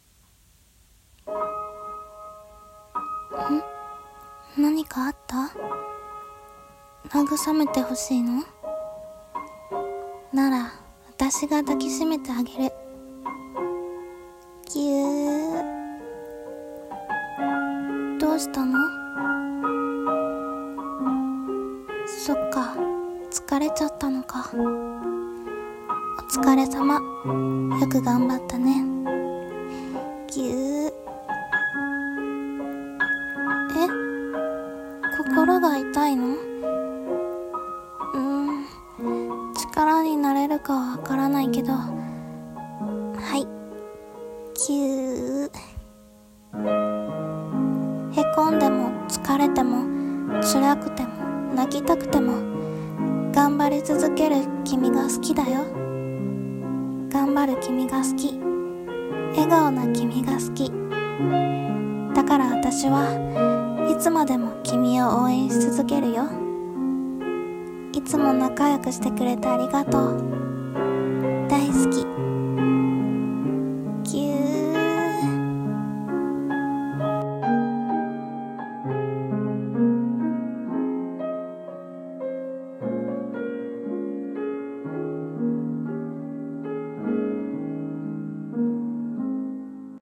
】【一人声劇】ぎゅー。